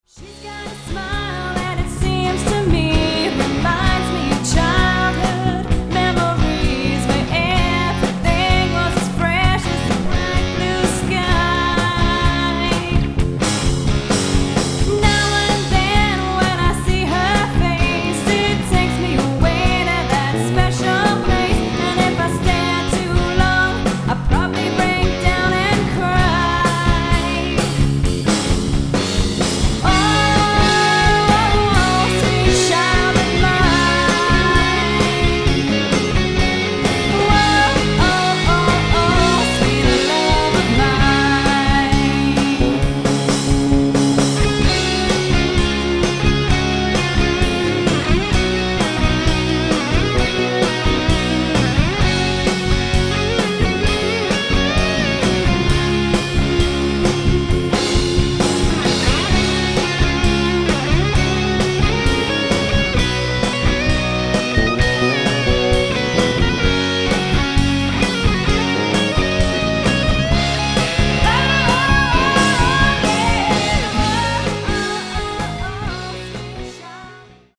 guitar
bass
drums
• Four-piece pop covers band